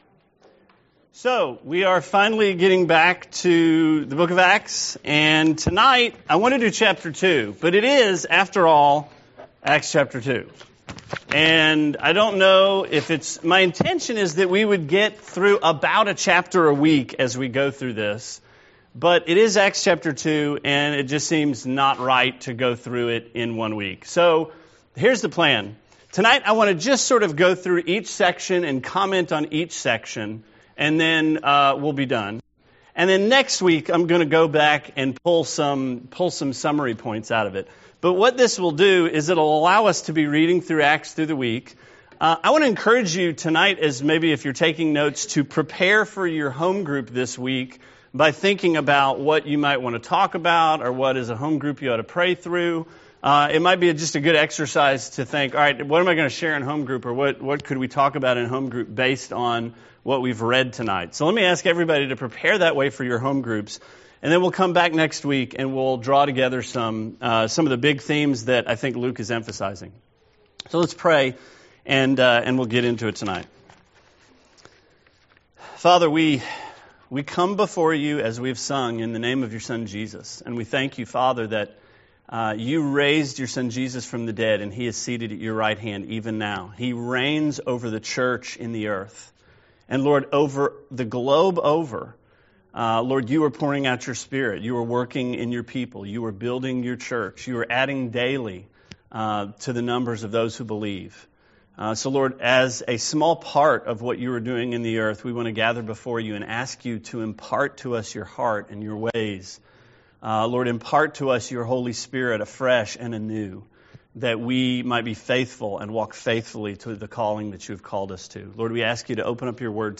Sermon 2/26: Acts: Chapter 2 part 1